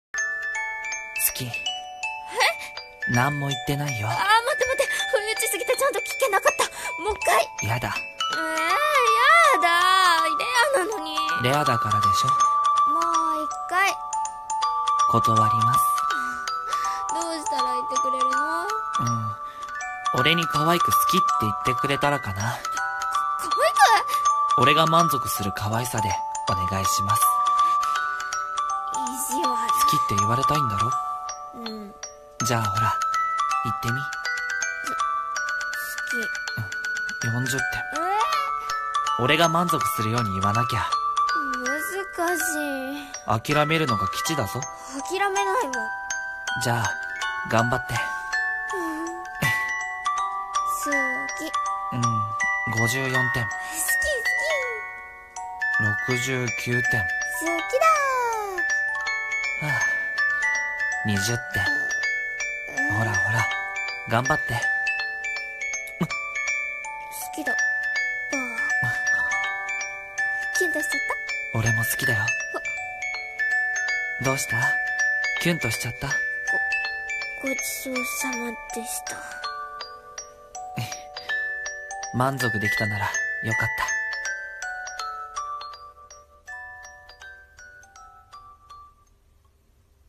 【声劇】好きって言ってほしいなら